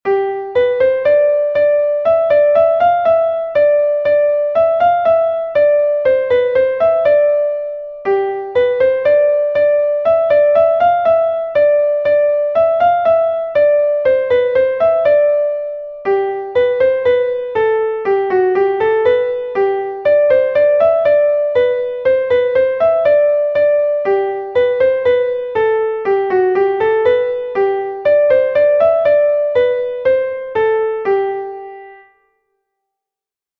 Kas a-barh